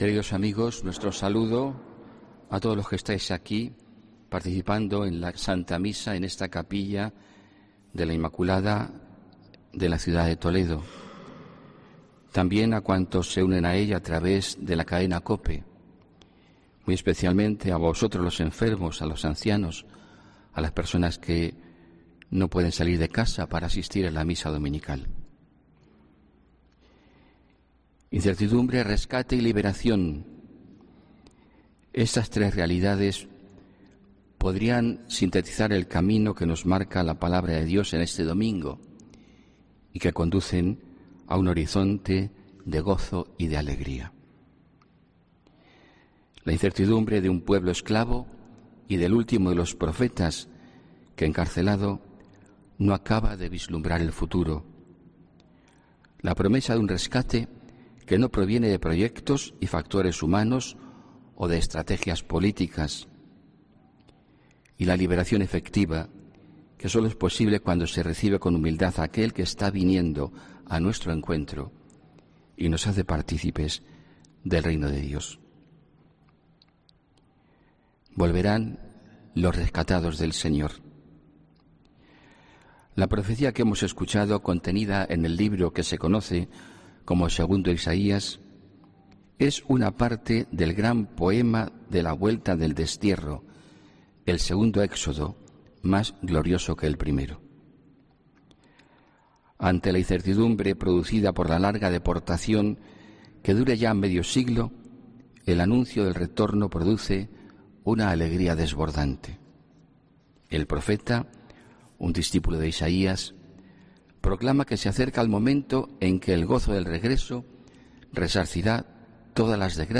HOMILÍA 15 DICIEMBRE 2019